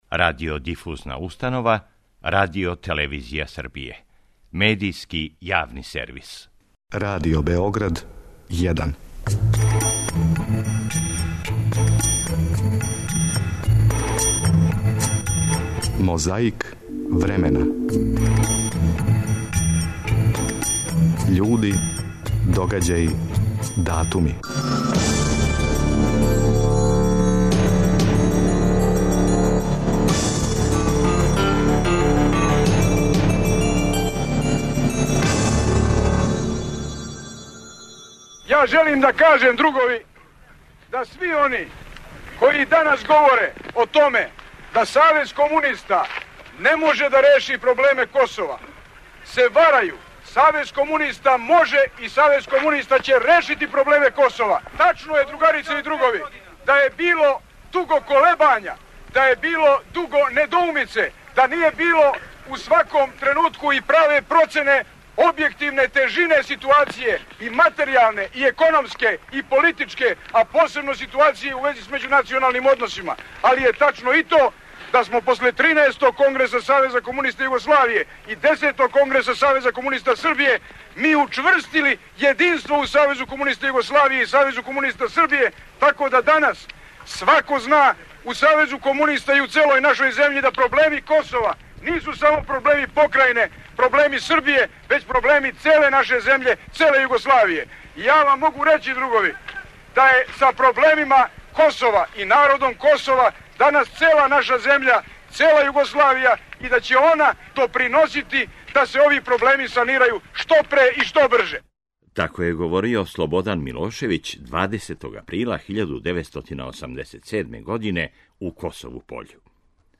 У архиви Радио Београда чува се фоно запис Милоша Црњанског који говори своју песму „Ламент над Београдом“.
Репортер на висини задатка.
А 19. априла 1970. године, у програму Радио Београда, разговарали су Драган Џајић и Том Џонс.
Превођење дискретно.